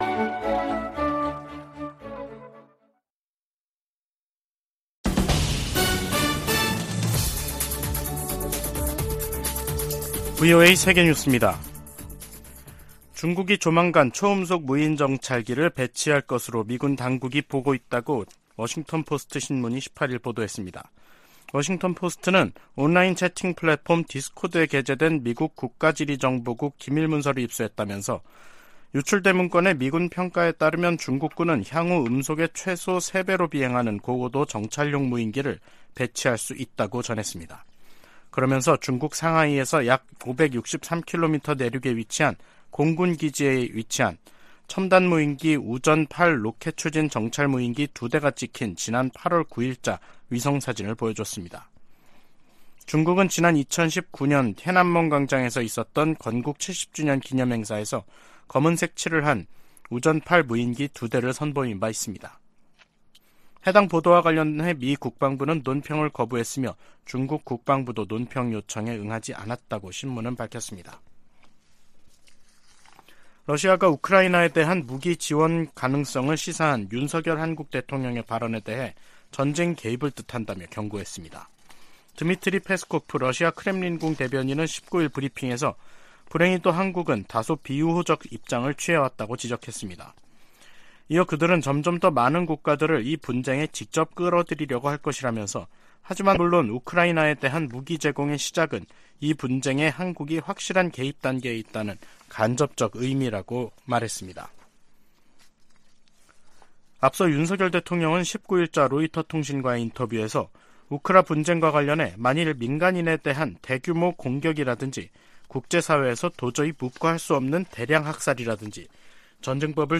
VOA 한국어 간판 뉴스 프로그램 '뉴스 투데이', 2023년 4월 19일 3부 방송입니다. 주한미군사령관은 북한 미사일이 워싱턴에 도달할 역량을 갖고 있으며, 7차 핵실험은 시간 문제라고 평가했습니다. 김정은 국무위원장이 첫 군사정찰위성 발사준비를 지시해 머지않아 위성발사 명분 도발에 나설 것으로 보입니다. 토니 블링컨 미 국무장관이 북한의 탄도미사일 발사와 핵 개발을 국제사회 공동 대응 과제로 꼽았습니다.